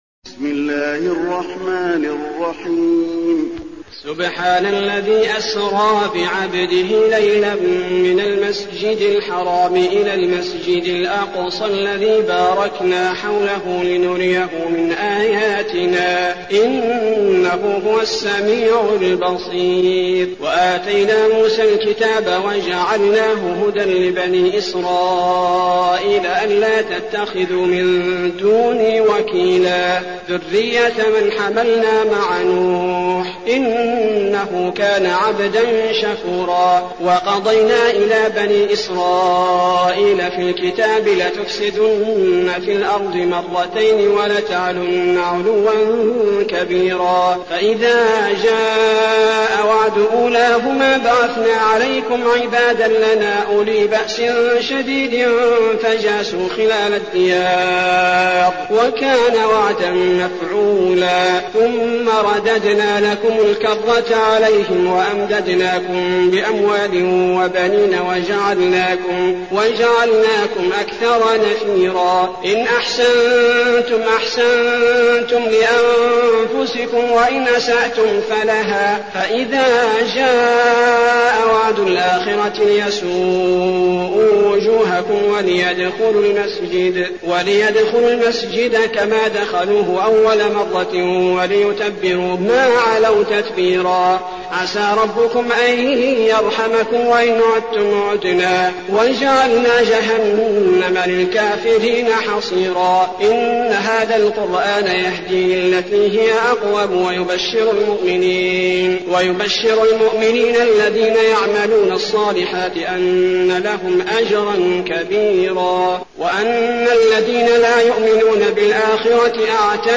المكان: المسجد النبوي الإسراء